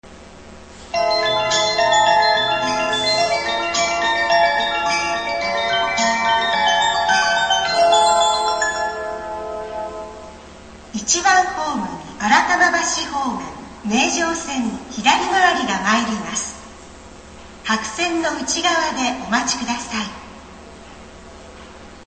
名城線左回り系統と名港線名古屋港方面行き（下り）ホームは女声、名港線金山方面行きと名城線右回り系統（上り）ホームは男声の接近放送が流れます。
冒頭には、路線・方面ごとに異なるメロディサイン（接近メロディ）が流れます。
１番ホーム旧接近放送